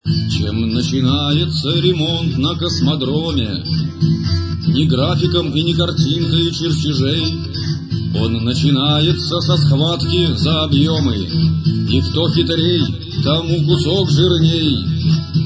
самодеятельные авторские песни
Аккомпанемент на соло гитаре
запись 1994 года, Космодром Байконур